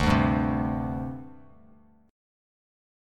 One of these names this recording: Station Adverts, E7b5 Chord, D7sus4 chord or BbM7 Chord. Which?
D7sus4 chord